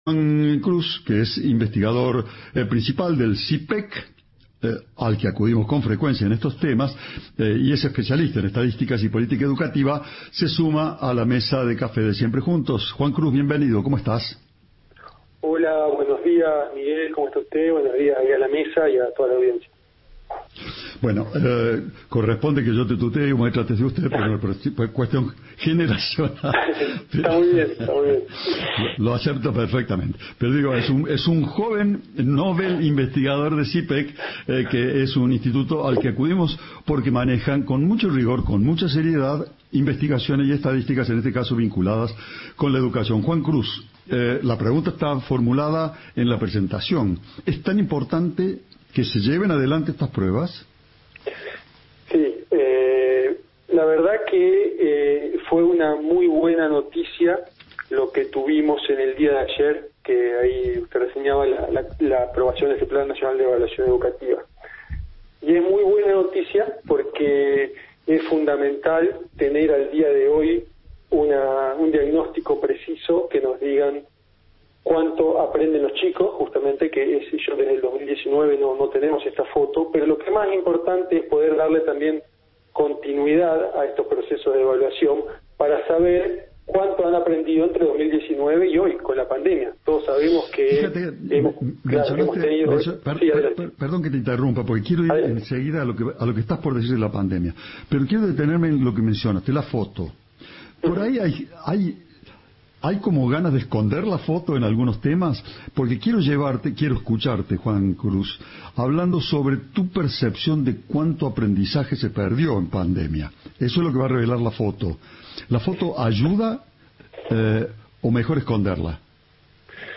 Radio Cadena 3 Argentina, 8 de julio de 2021